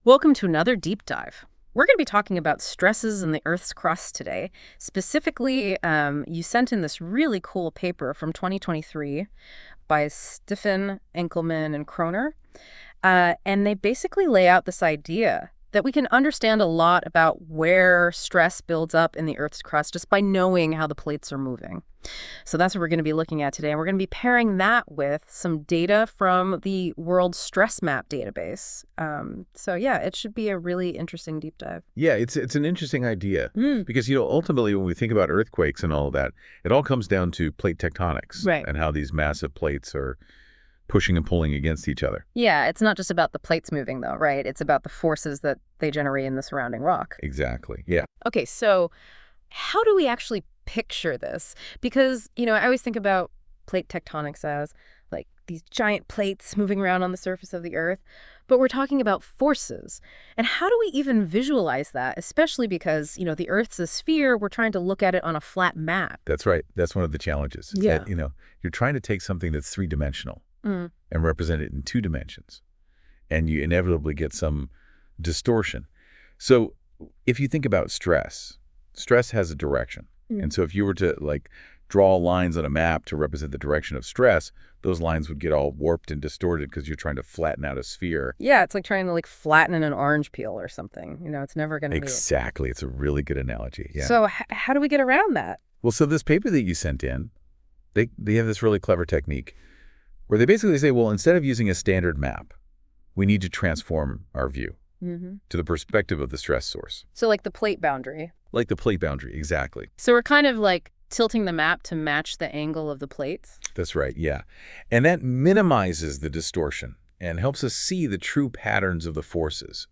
Audio AI-generated 1 audio summary